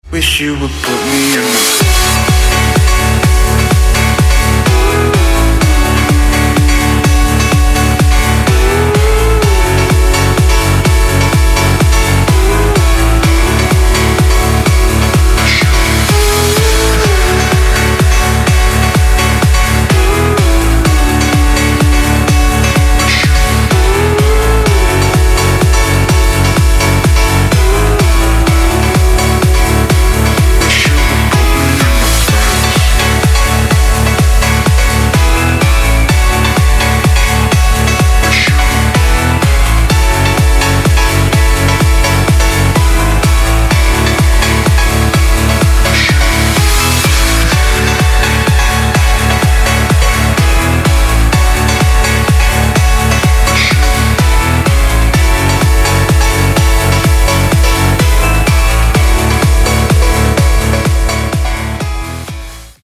Club House
progressive house